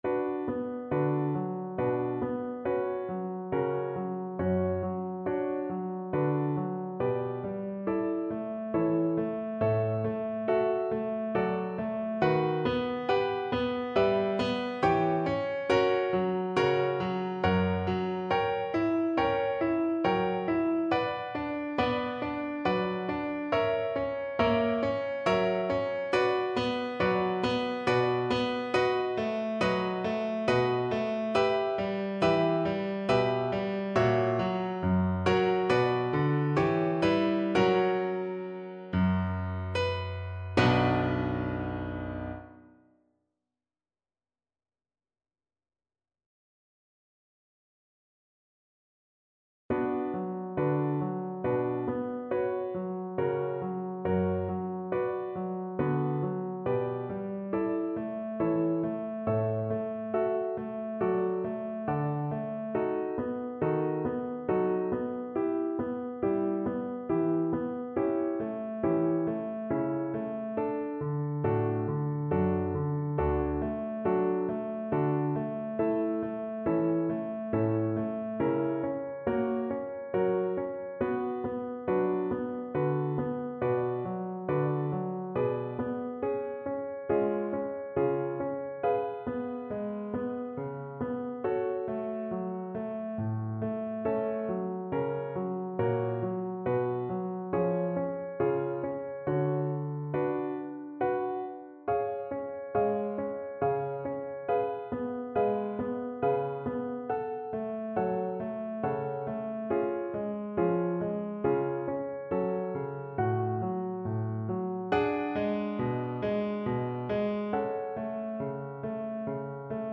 Free Sheet music for Violin
Play (or use space bar on your keyboard) Pause Music Playalong - Piano Accompaniment Playalong Band Accompaniment not yet available transpose reset tempo print settings full screen
Violin
Andante stretto (=c.69)
3/4 (View more 3/4 Music)
B minor (Sounding Pitch) (View more B minor Music for Violin )
Classical (View more Classical Violin Music)